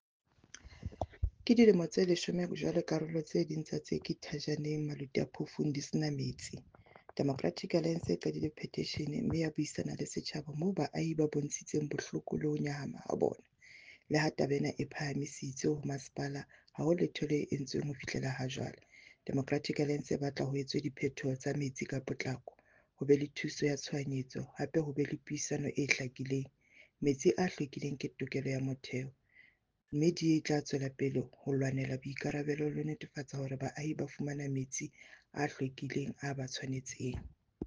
Sesotho soundbites by Cllr Ana Motaung and